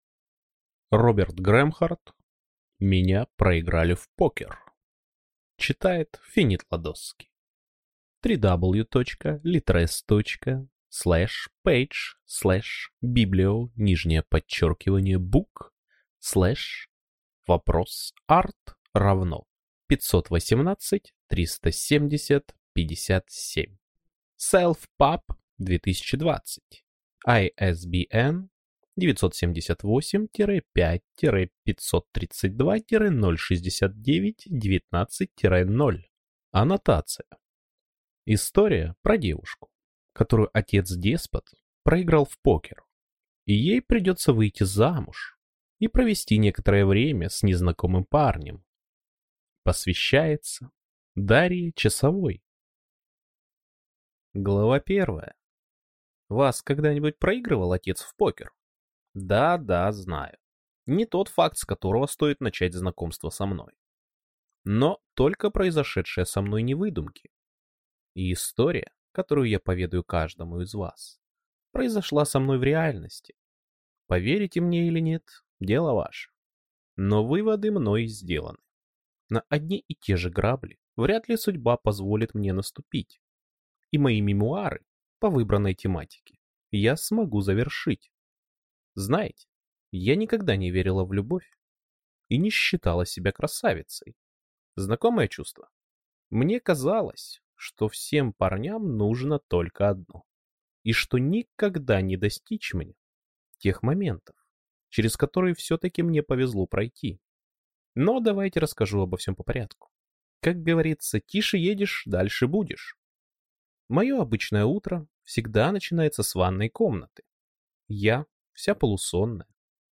Аудиокнига Меня проиграли в покер!